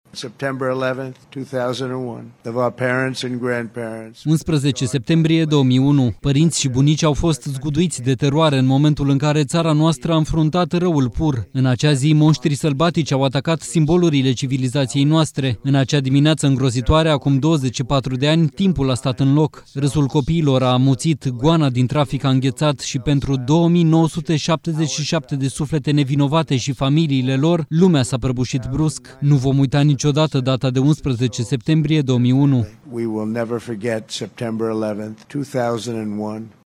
În cadrul discursului ținut în memoria victimelor, președintele american Donald Trump a anunțat că îl va decora post-mortem pe Charlie Kirk, asasinat aseară, cu Medalia Prezidențială a Libertății.